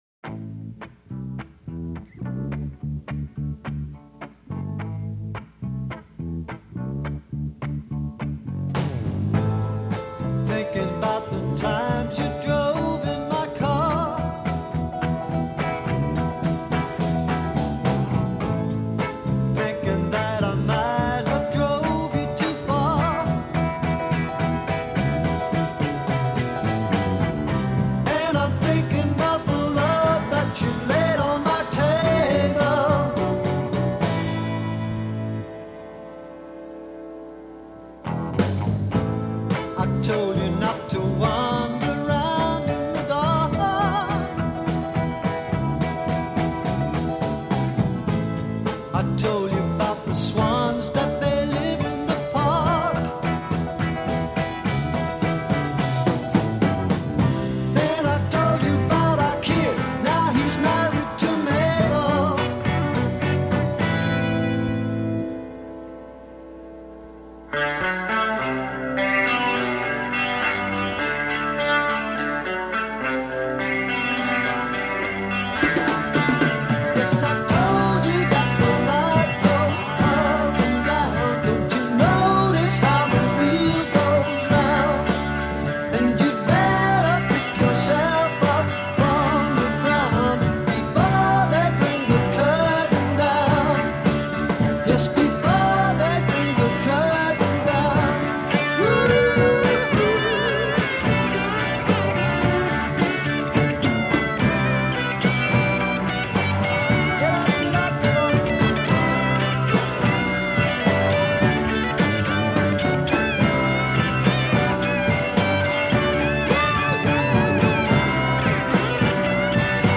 Recorded at IBC Studios, London, 2 October 1968.
Introduction   Guitar & bass establish accompanying pattern.
Add vocals when ensemble reaches tutti.   c
Bridge   Guitar solo over bridge pattern
End abruptly with chord sustain. d
Transatlantic Psychedelic Blues